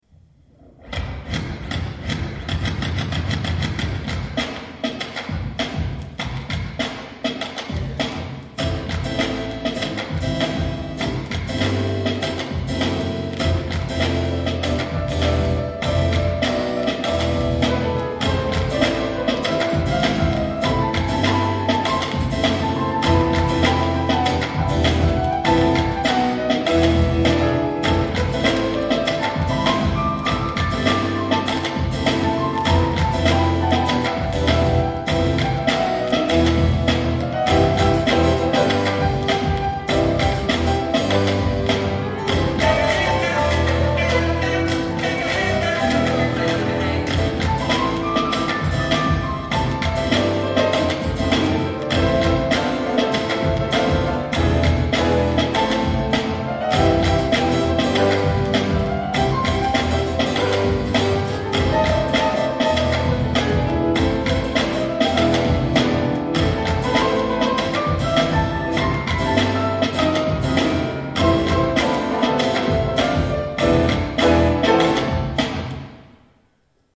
hip-hop �� kanon �� jazz
beat-box